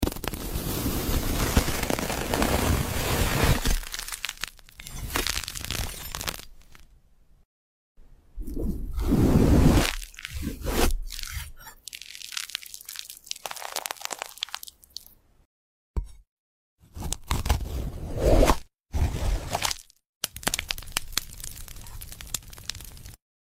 Asmr food cutting relexing sound effects free download